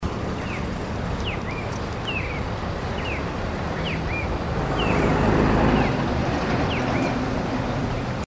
Cardenal Común (Paroaria coronata)
Nombre en inglés: Red-crested Cardinal
Localidad o área protegida: Reserva Ecológica Costanera Sur (RECS)
Condición: Silvestre
Certeza: Vocalización Grabada